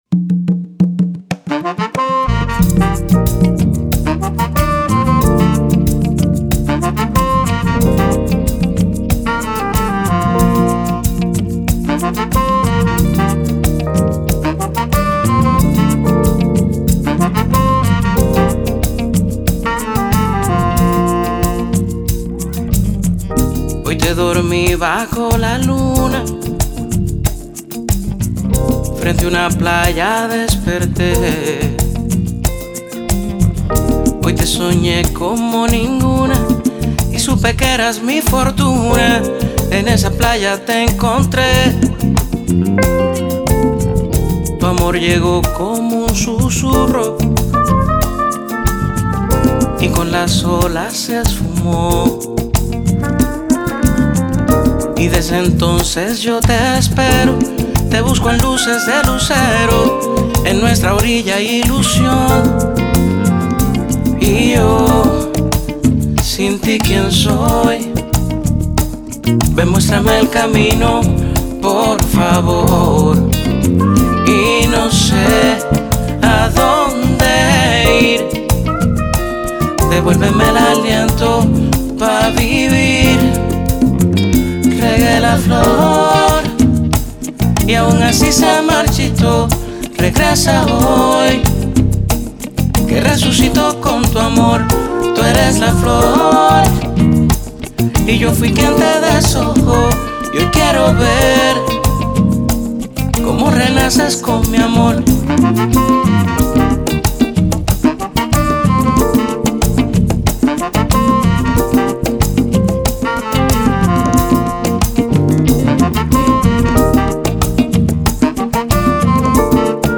” blending Afro-Caribbean rhythms and storytelling.
a Merengue/Lounge
saxophone
trumpet
trombone
bass
electric guitar
congas
drums
background vocals
FILE: Latin Jazz Vocal